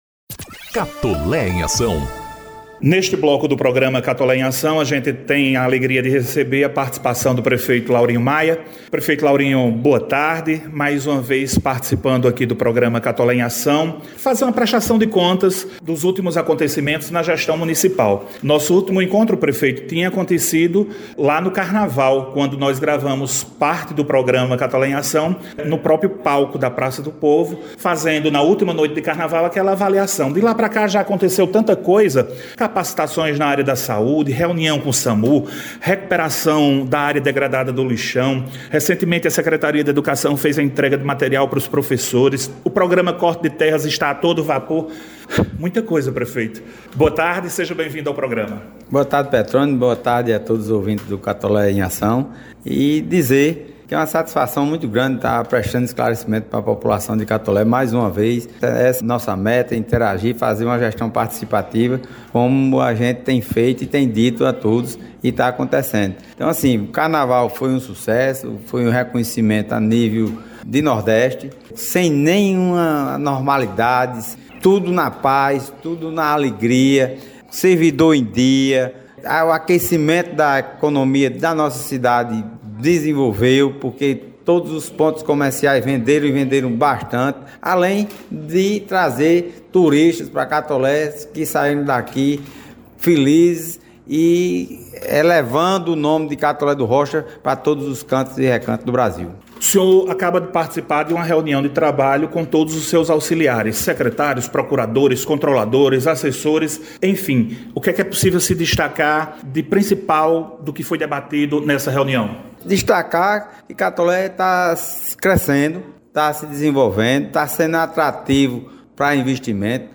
Prefeito Laurinho fala dos investimentos, ações e serviços em execução e das futuras obras em Catolé do Rocha. Ouça na íntegra a entrevista!